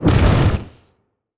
fireball_blast_projectile_spell_04.wav